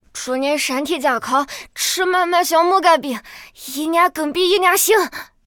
c01_6卖艺小孩B_1.ogg